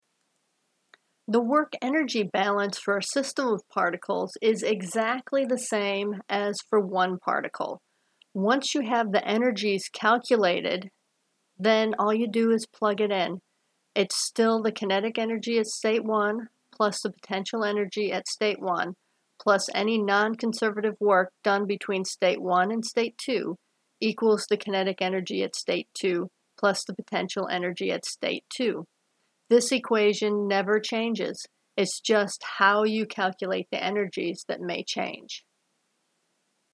Lecture content